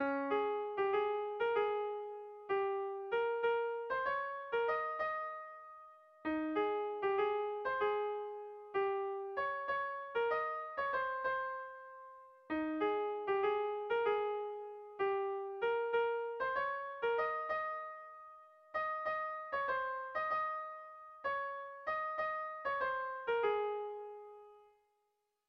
Sentimenduzkoa
Zortziko txikia (hg) / Lau puntuko txikia (ip)
A1A2A3B